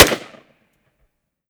5.56 M4 Rifle - Gunshot A 002.wav